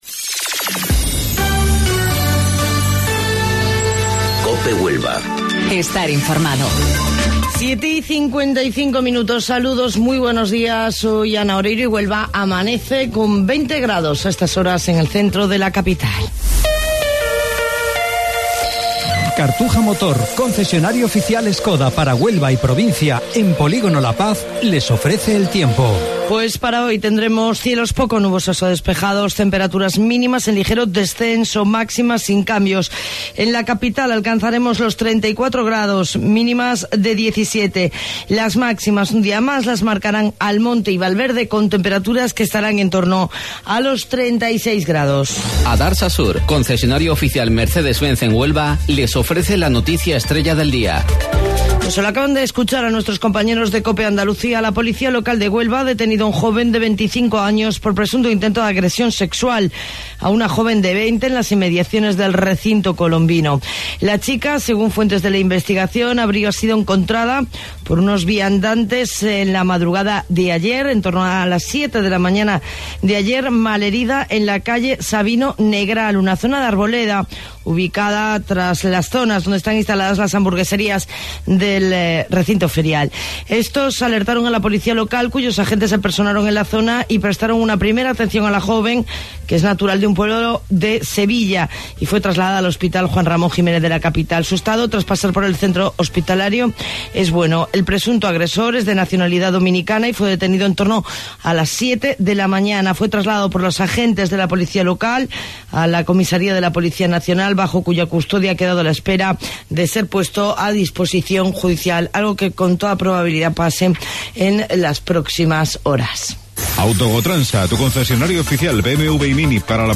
AUDIO: Informativo Local 07:55 del 2 de Agosto